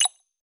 Modern Click 1.wav